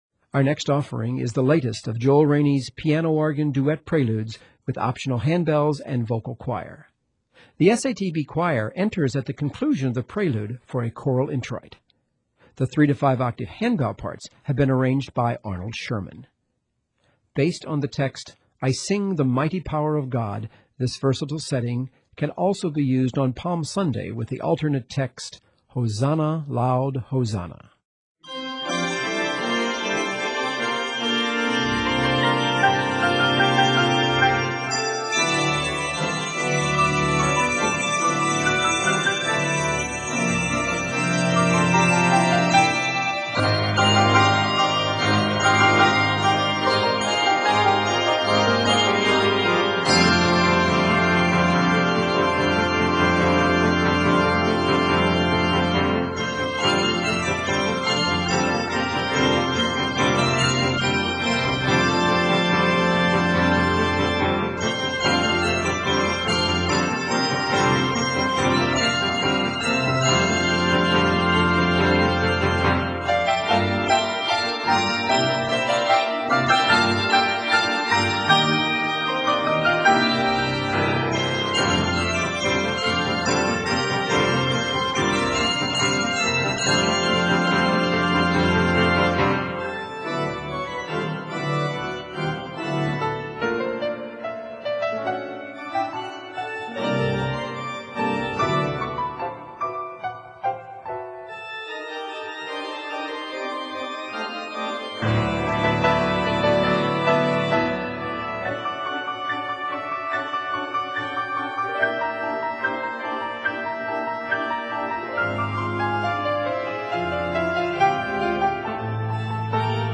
This festive prelude
piano/organ duets
optional 3 to 5-octave handbells